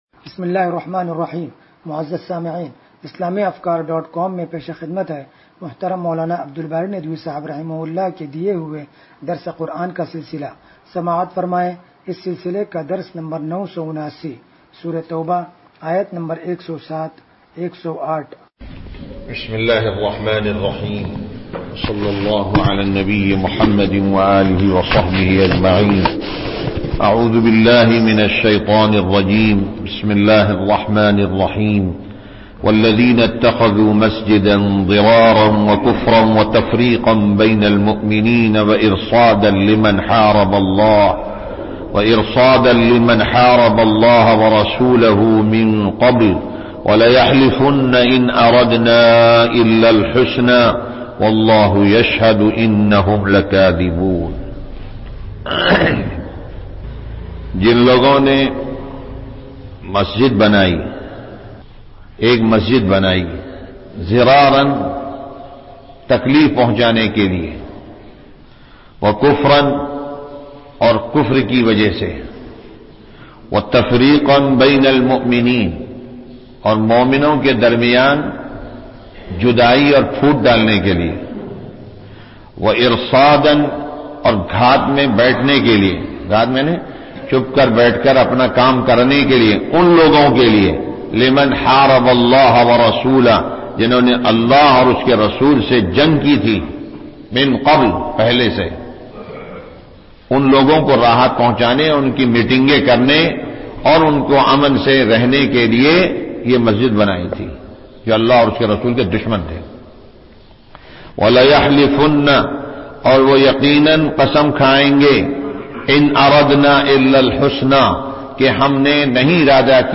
درس قرآن نمبر 0979